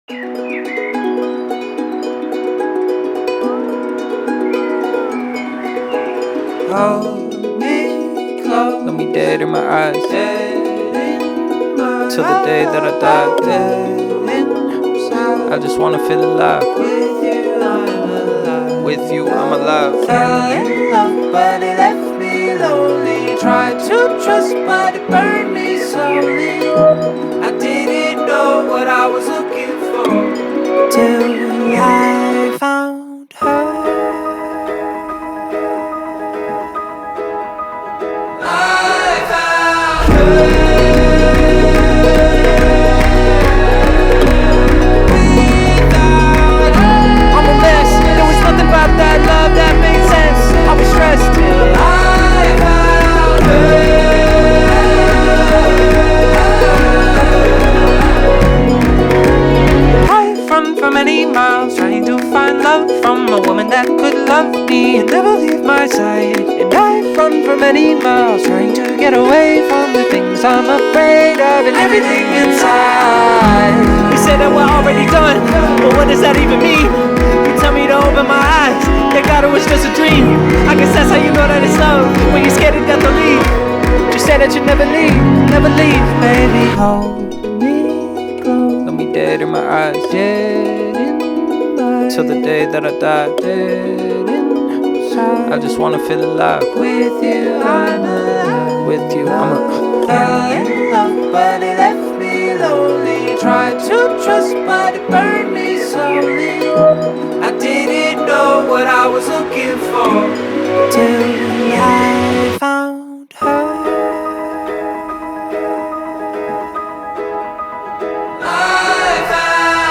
از اون آهنگای حال خوب کن و با انرژیه
آهنگ بسیار ساده و زیباست